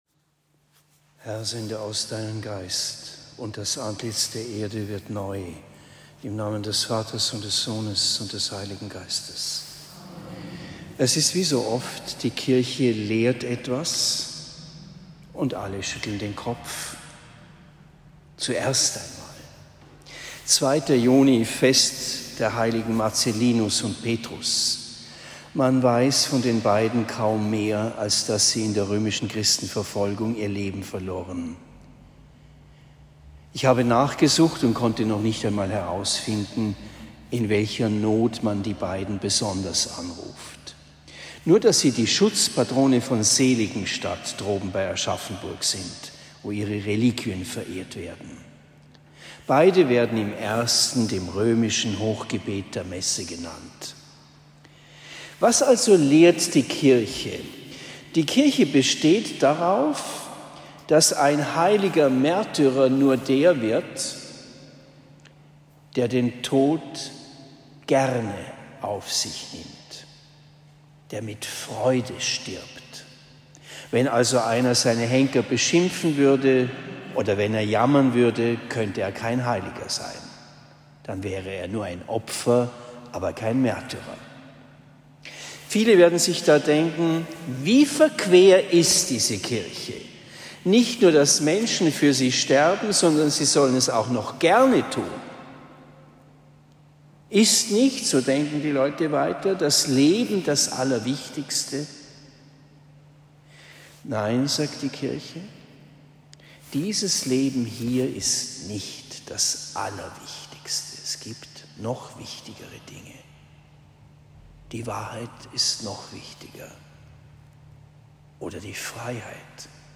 Predigt in Oberndorf am 02. Juni 2023